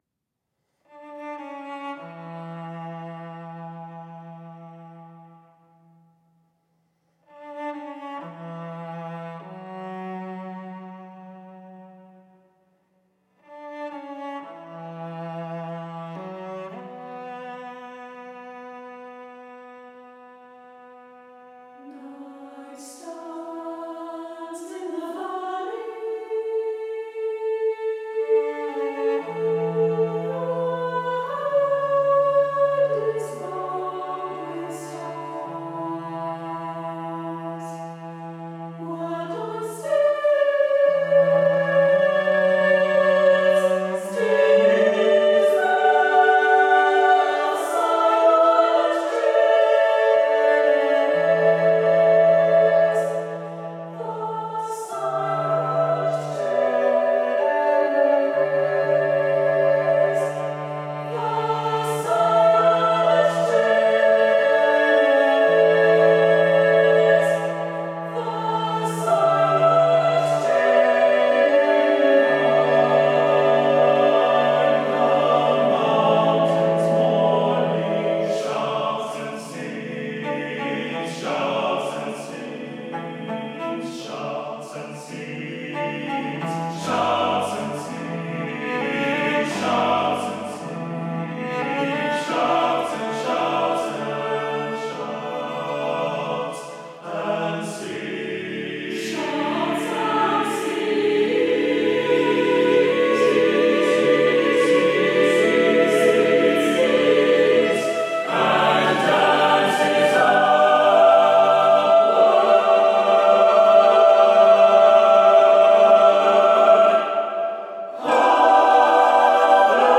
SATB Chorus with Divisi & Cello